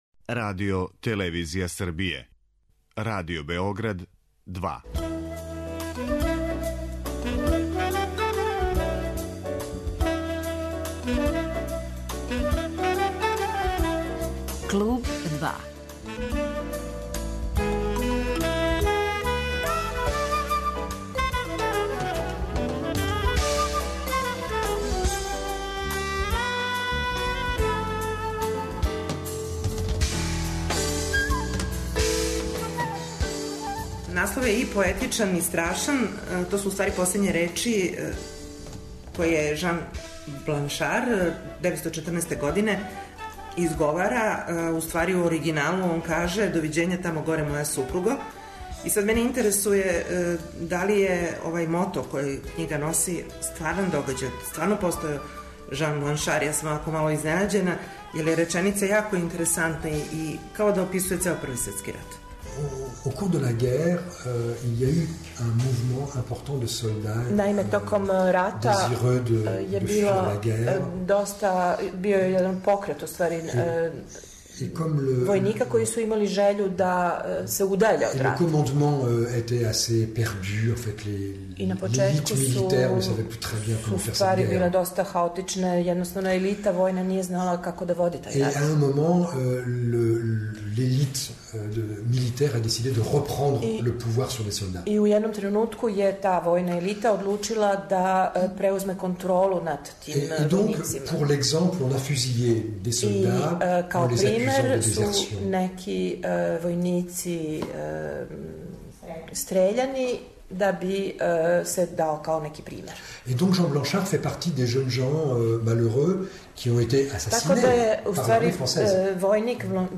Гост 'Клуба 2': Пјер Леметр